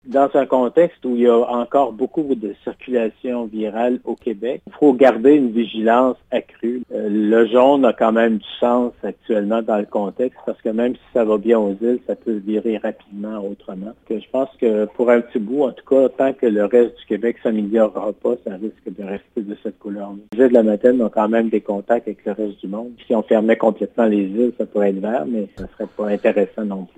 Il précise que le niveau d’alerte jaune est plus approprié étant donné la présence du virus dans plusieurs régions du Québec et la possibilité de voyager entre les régions :